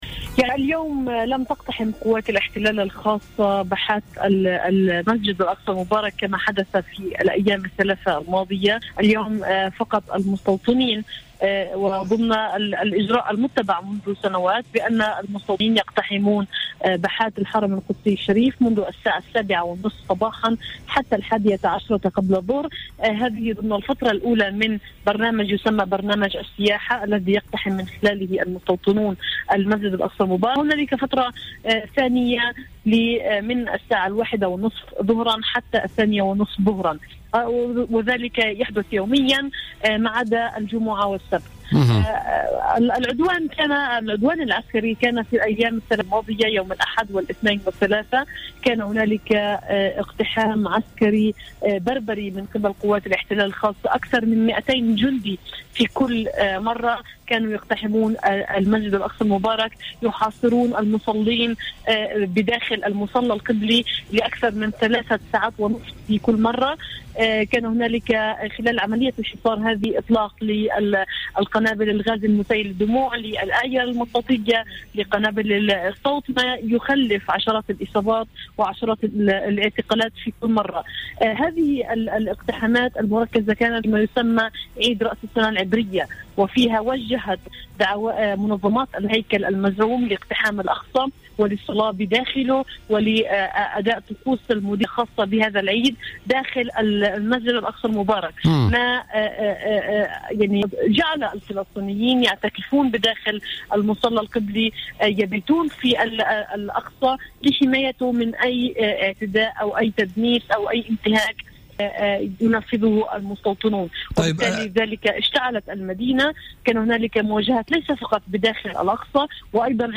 مداخلة لها اليوم الأربعاء في برنامج "بوليتيكا"